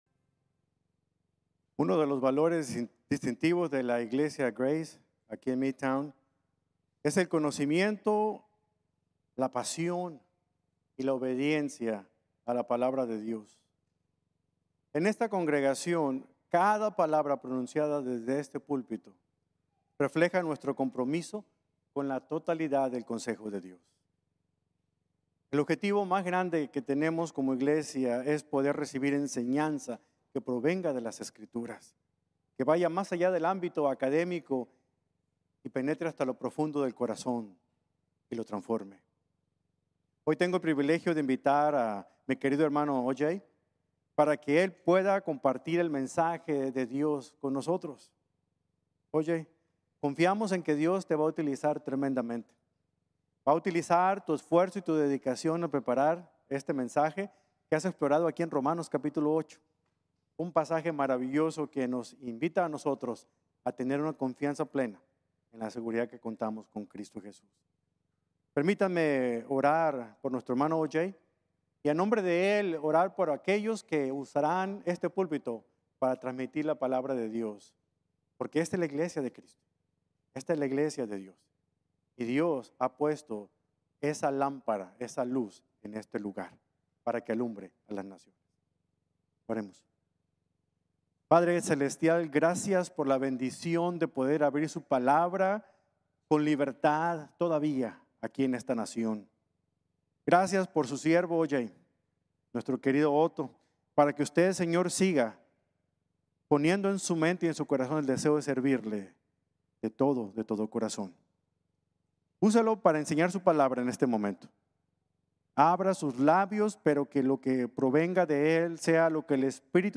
Fortaleza Divina en la Debilidad: Guía, Propósito y Amor | Sermon | Grace Bible Church
Midtown Campus